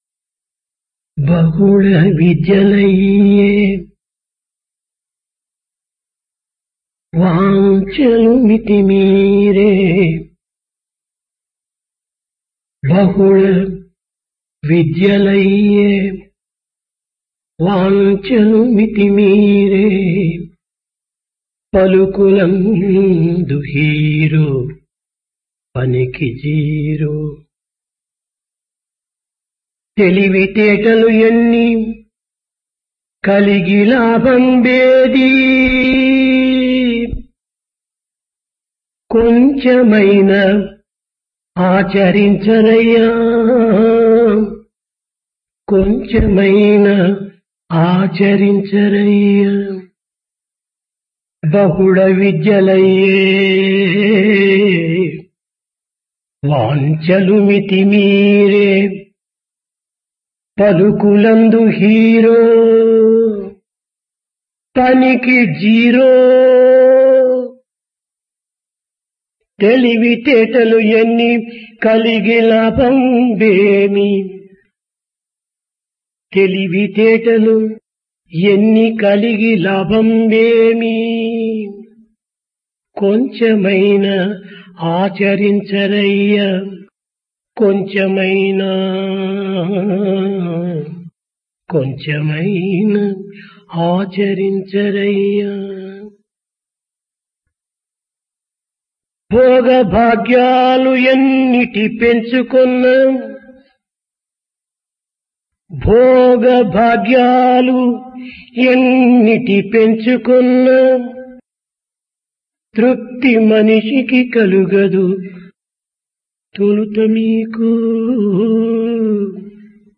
Discourse
Occasion Sports Meet